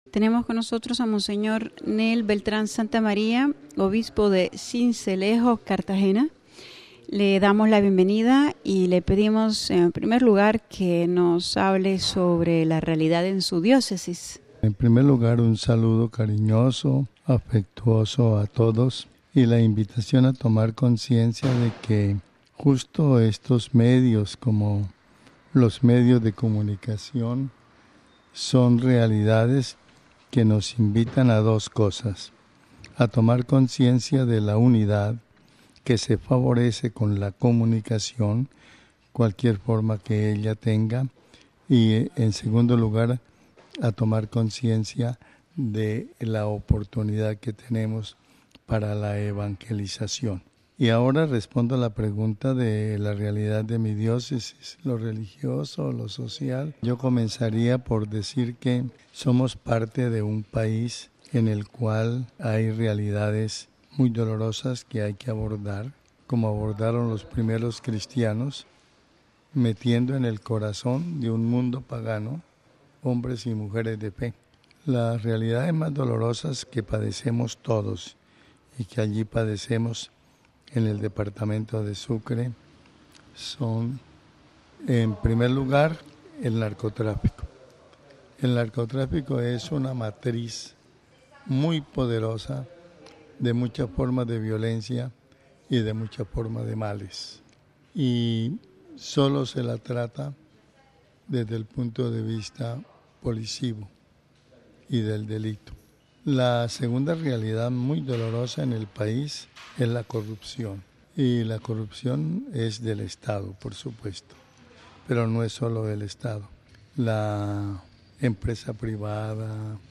(RV).- (AUDIO) Seguimos nuestras entrevistas a los obispos colombianos, en Roma en su visita ad limina, con nosotros el obispo de Sincelejo, Mons. Nel Beltrán Santamaría, a quien le preguntamos en primer lugar sobre la realidad de su diócesis y un mensaje a los fieles.